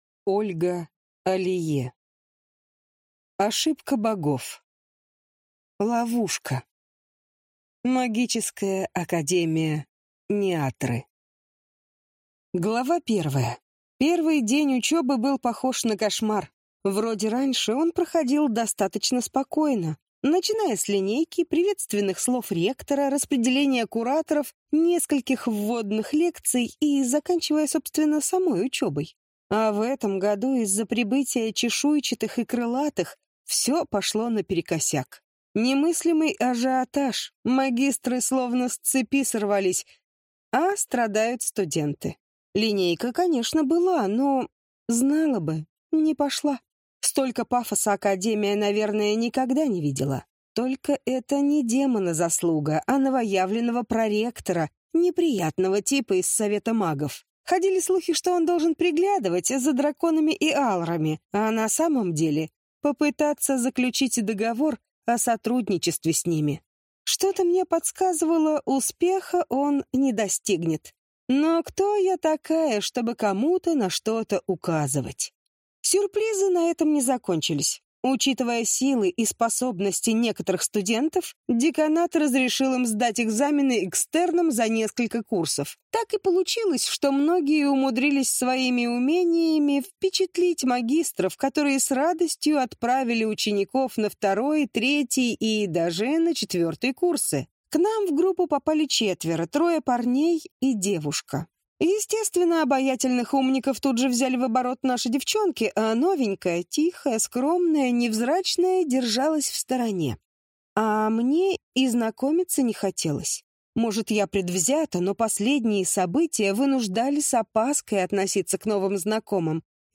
Аудиокнига Ошибка богов. Ловушка | Библиотека аудиокниг